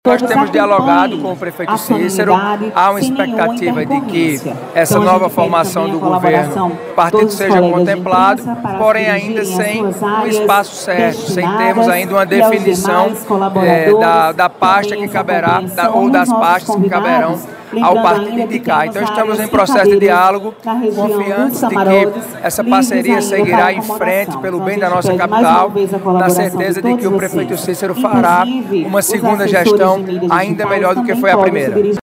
A informação foi confirmada nesta segunda-feira (13) pelo presidente estadual do partido, deputado federal Hugo Motta, durante entrevista ao programa Correio Debate (98 FM).